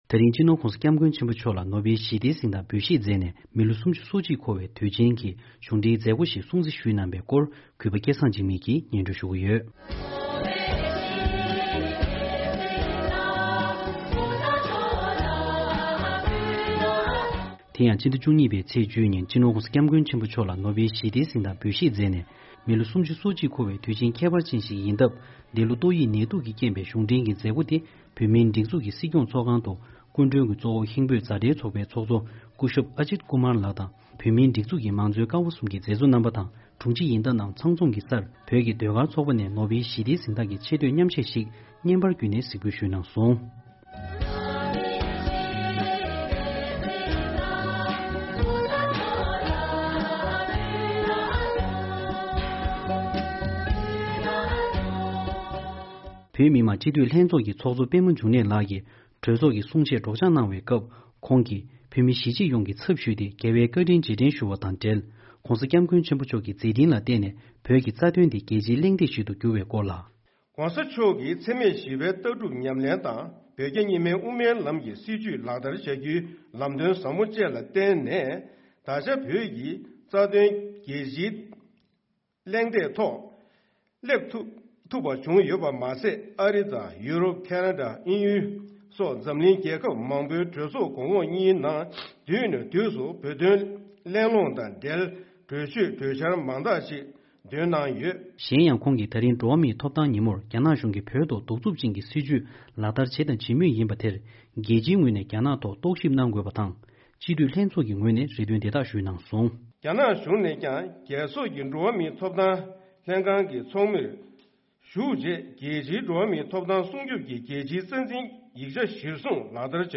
བཞུགས་སྒར་ནས་འདི་གའི་གསར་འགོད་པ་